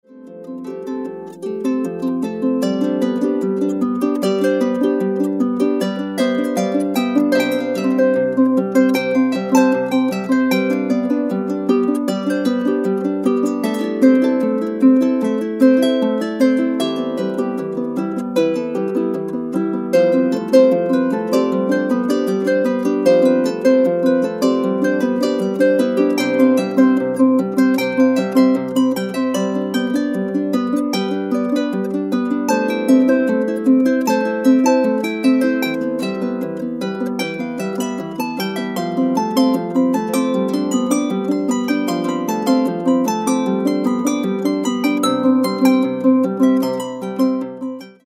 (Celtic harp)  3'533.57 MB1.70 Eur